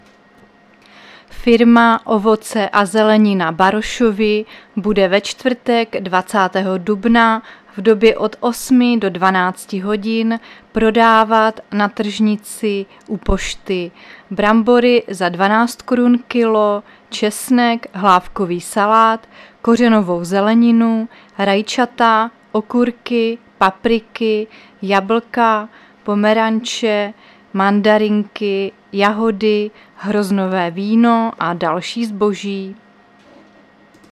Záznam hlášení místního rozhlasu 19.4.2023